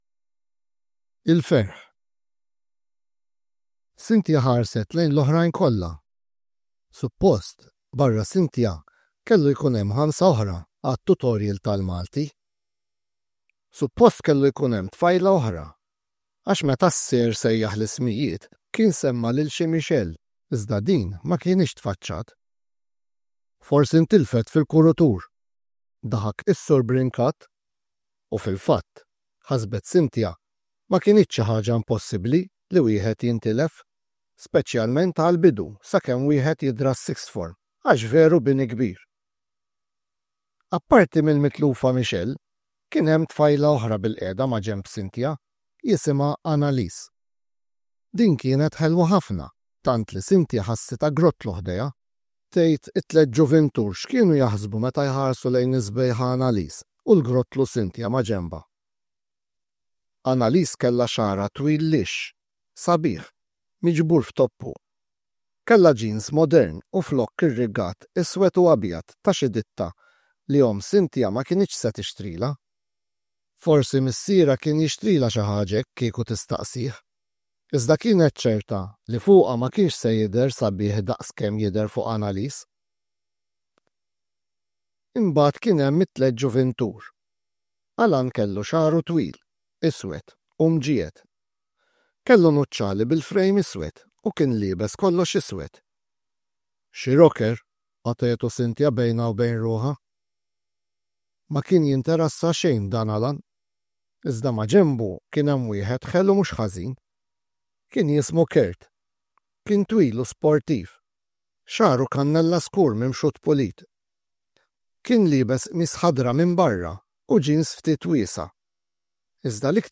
Kull taħriġ jinkludi s-silta moqrija, il-karta tat-taħriġ għall-istudenti u l-karta tal-għalliema bir-risposti.